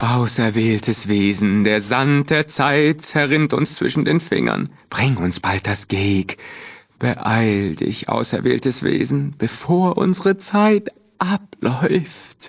in: Fallout 2: Audiodialoge Datei : HAK031.ogg Quelltext anzeigen TimedText Versionsgeschichte Diskussion Tritt unserem Discord bei und informiere dich auf unserem Twitter-Kanal über die aktuellsten Themen rund um Fallout!